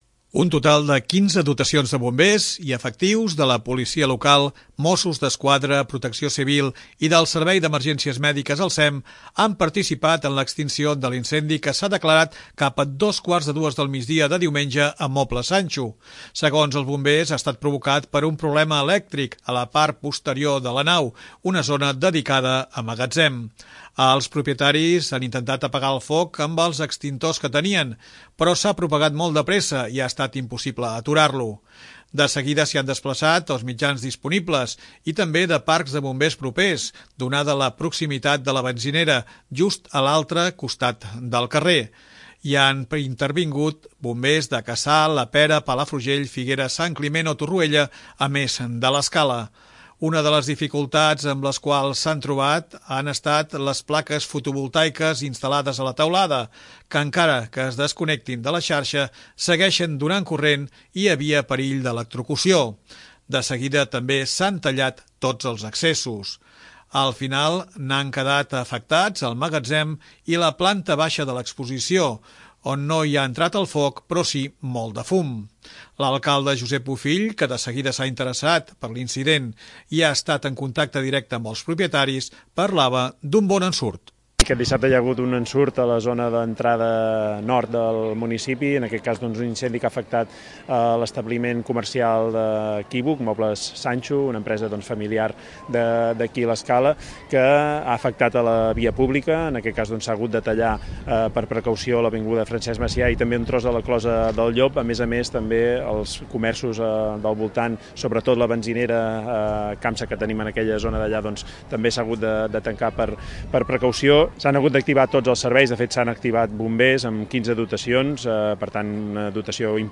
L'alcalde Josep Bofill, que de seguida s'ha interessat per l'incident i ha estat en contacte directe amb els propietaris, parlava d'un bon ensurt.